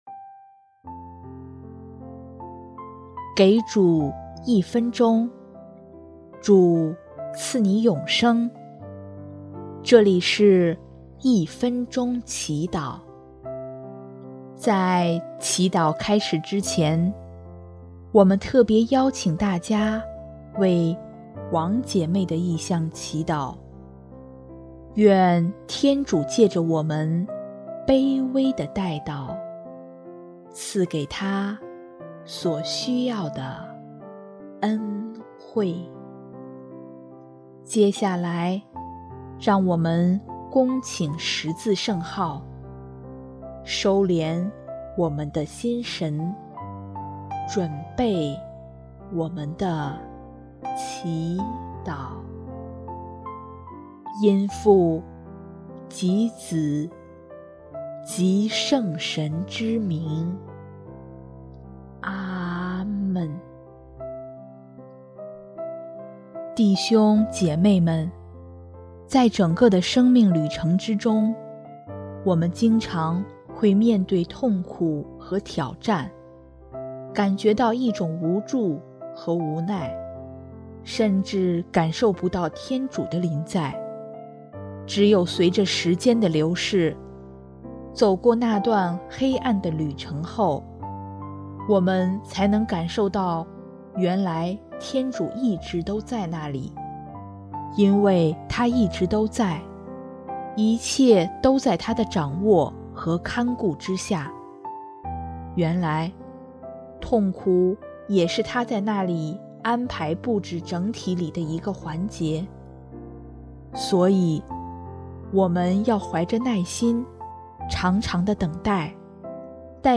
主日赞歌